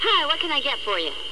下列紅色部份為省音，已省去不唸；而字串連結為連音。